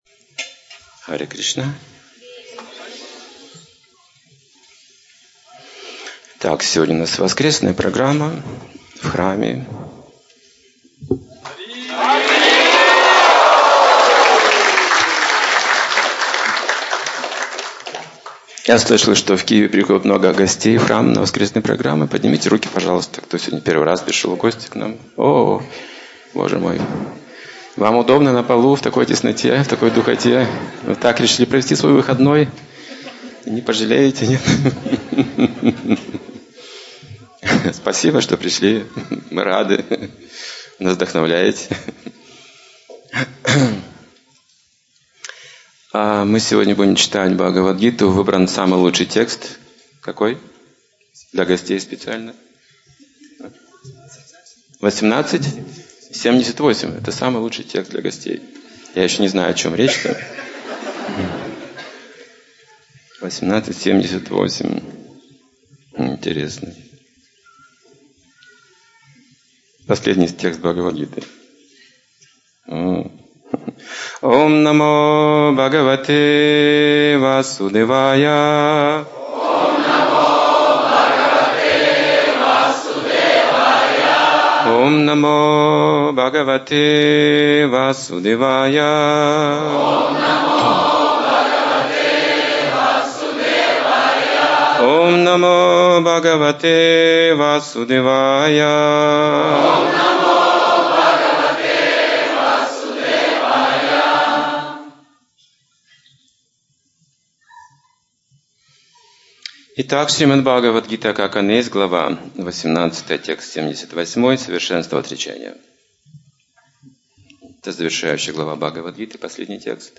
Темы, затронутые в лекции: Важность правильного примера своей жизни. Пять истин Бхагавад Гиты.